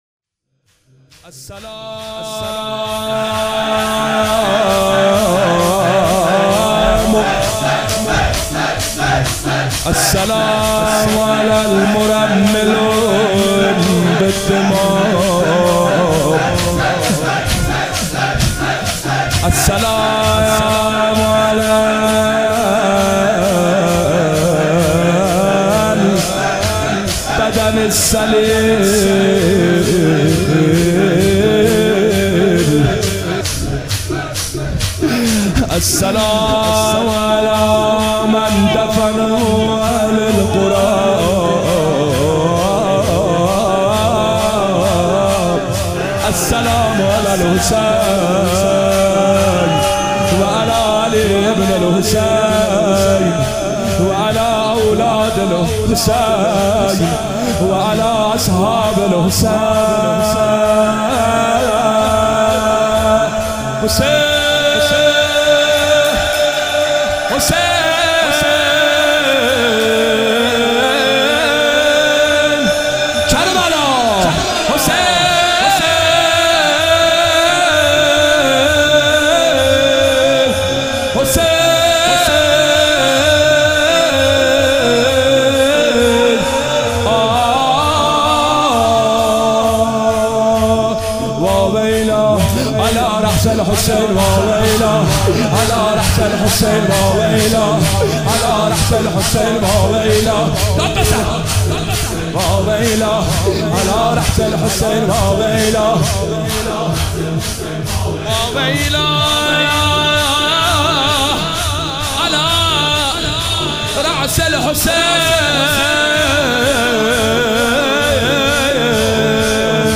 مداحی و نوحه
مداحی فاطمیه
(شور)